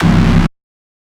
Synth Stab 15 (C).wav